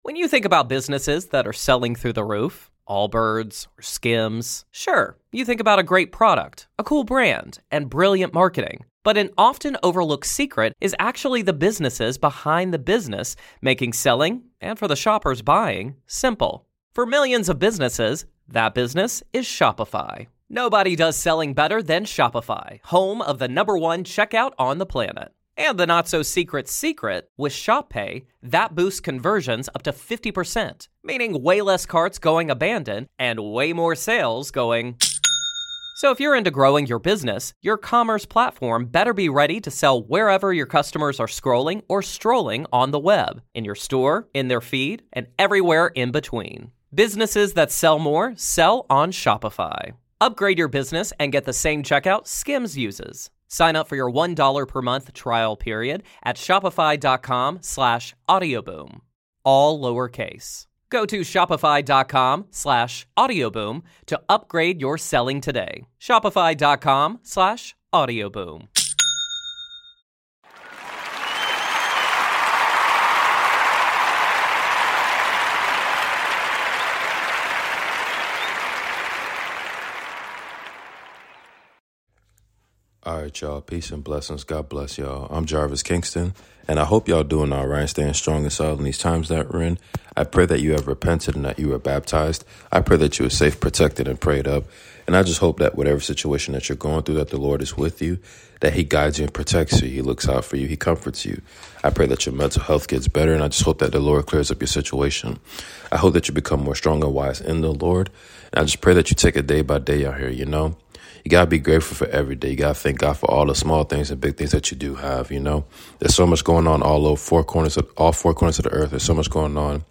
1 Kings reading continuation!